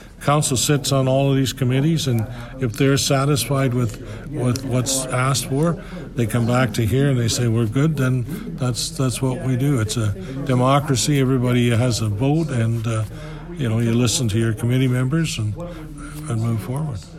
Mayor Jim Harrison says despite the increases, councillors seem content with the spending plan.